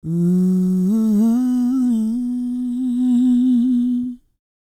E-CROON P327.wav